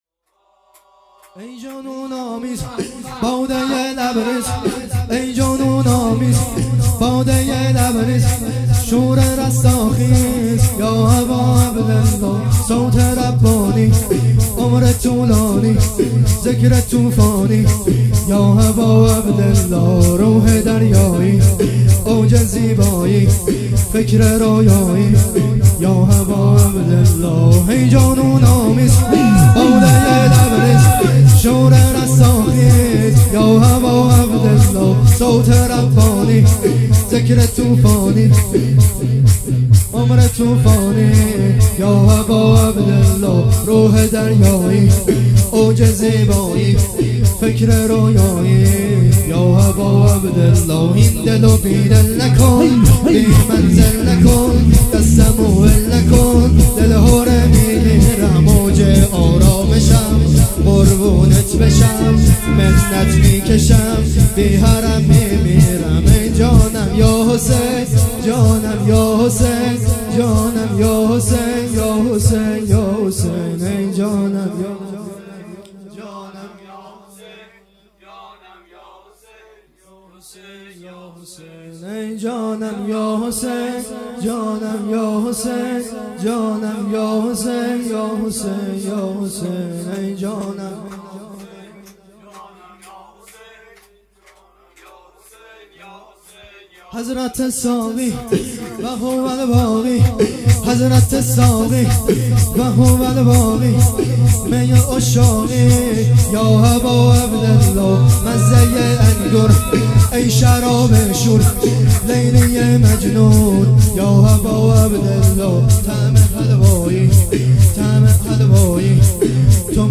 شور
شب لیله الرغائب 6 بهمن 1401